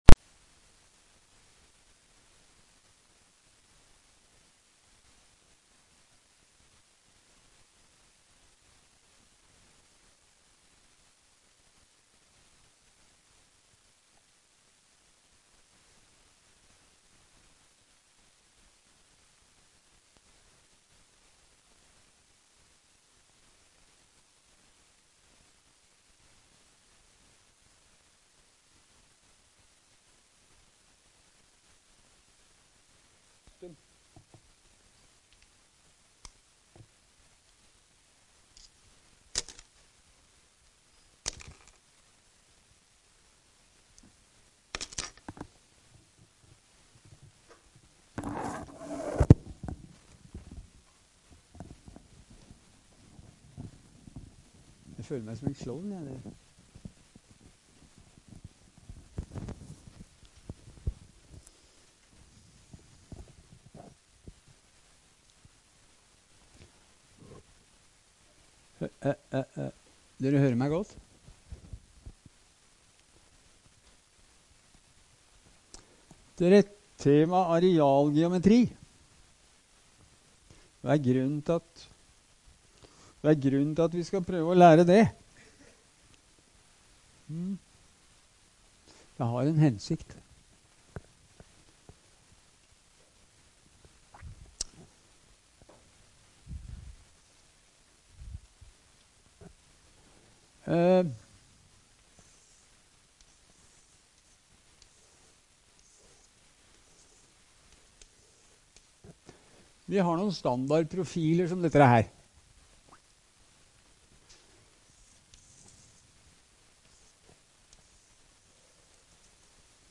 Mekanikk - NTNU Forelesninger på nett
Rom: K102